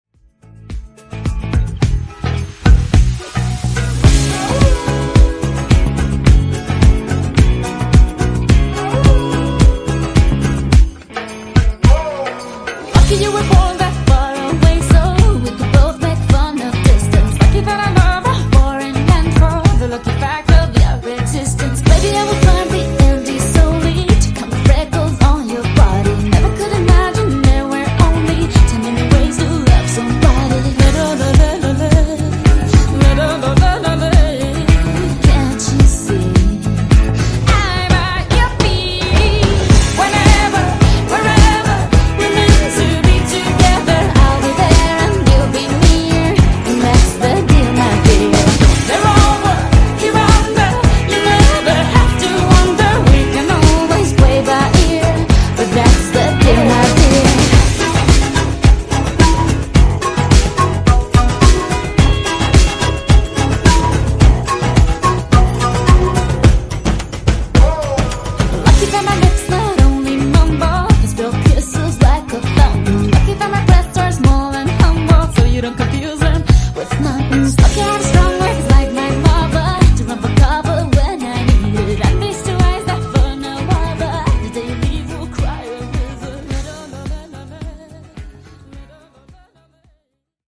Genres: RE-DRUM , REGGAETON